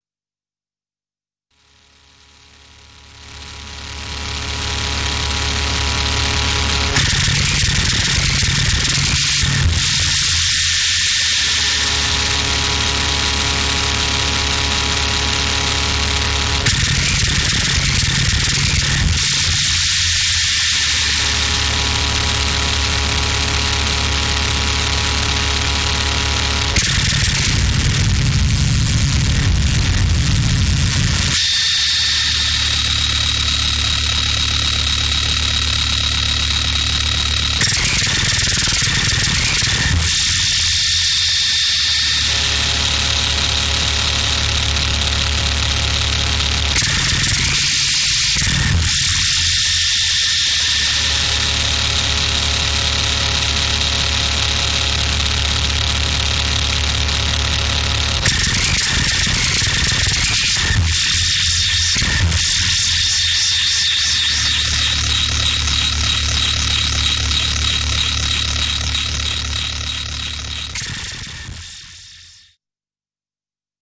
industrial und artverwandter bereich
Rhythm-Industrie/Power Electronics/Noise.